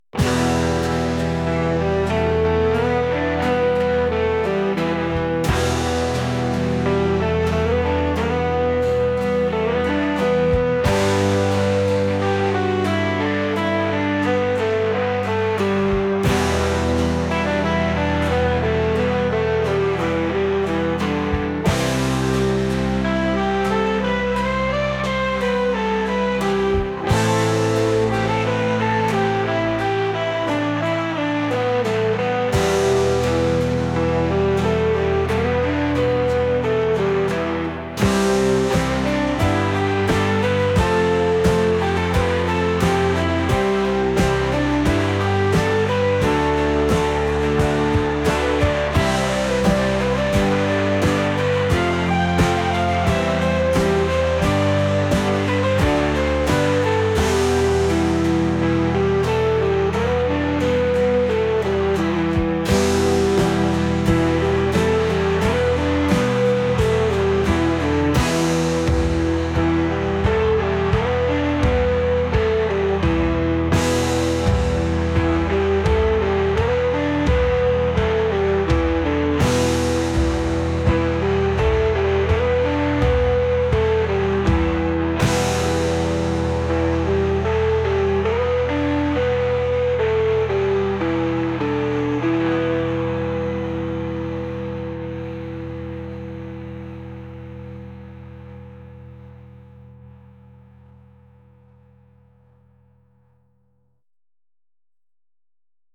folk | rock | ambient